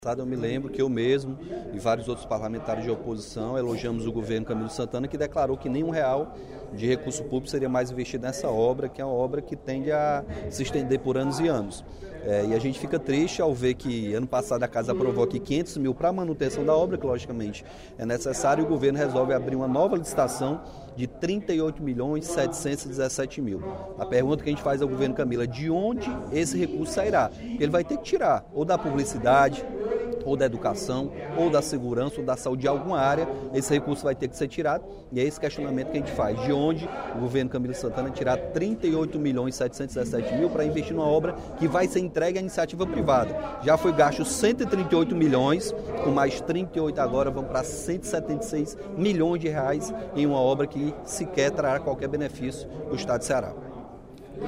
O deputado Capitão Wagner (PR) cobrou, durante o primeiro expediente da sessão plenária da Assembleia Legislativa desta terça-feira (18/04), explicações do governador Camilo Santana sobre a abertura de licitação para obras do Acquario.